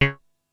描述：通过Modular Sample从模拟合成器采样的单音。
Tag: CSharp5 MIDI音符-73 DSI-利 合成器 单票据 多重采样